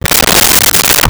Dresser Drawer Opened 03
Dresser Drawer Opened 03.wav